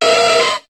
Cri de Dynavolt dans Pokémon HOME.